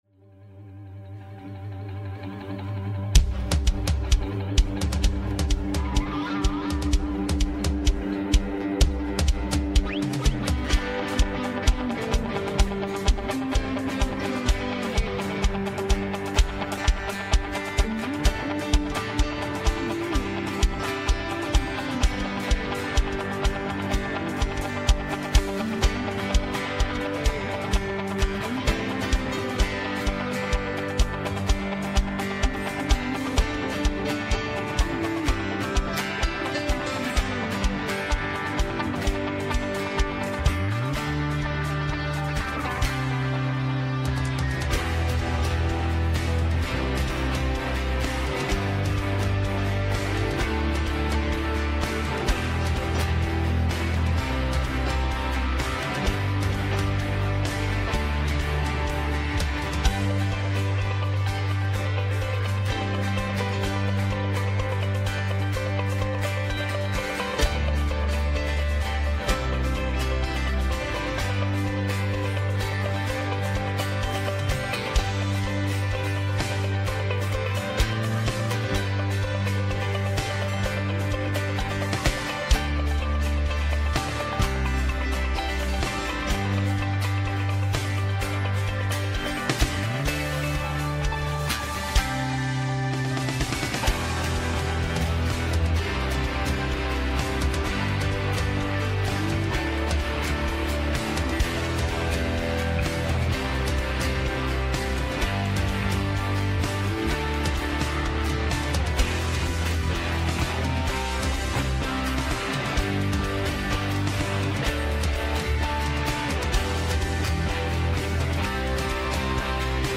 Westgate Chapel Sermons